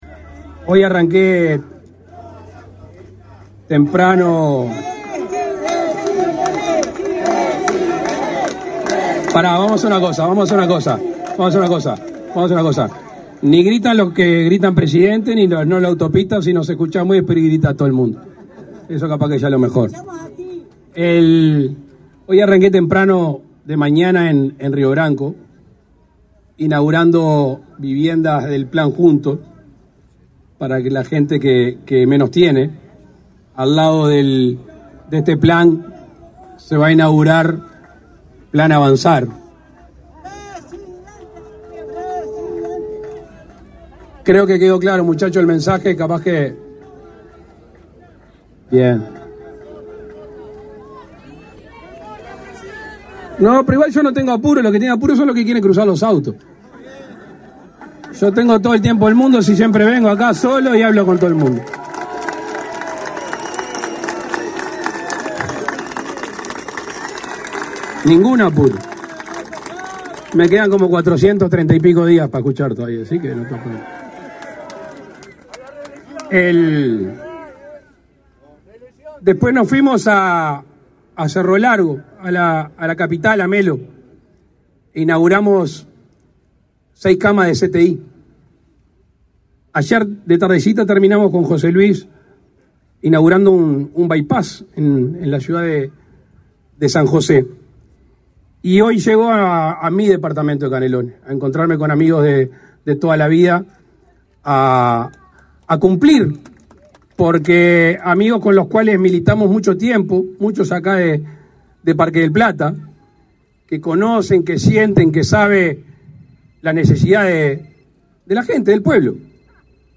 Palabras del presidente de la República, Luis Lacalle Pou
El presidente de la República, Luis Lacalle Pou, participó, este 22 de diciembre, en la inauguración de un intercambiador en Parque del Plata.